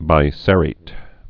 (bī-sĕrāt)